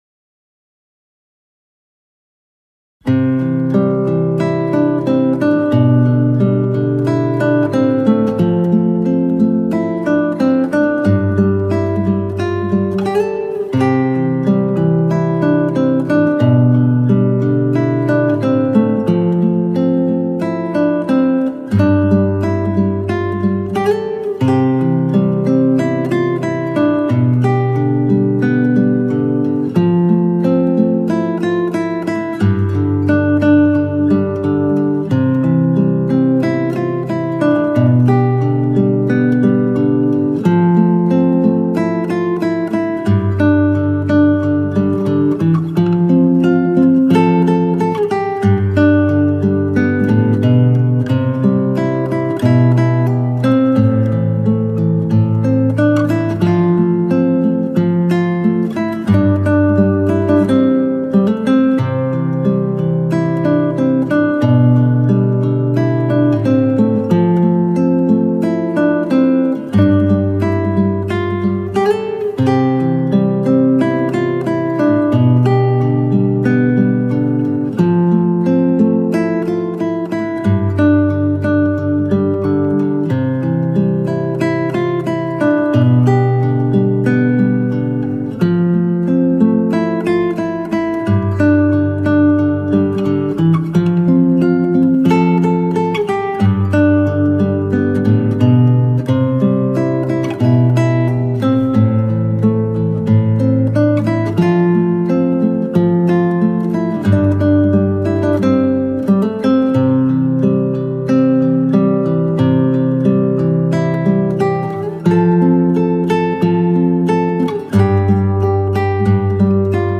Audio Clip from the Tutorial
Standard Tuning - 4//4 Time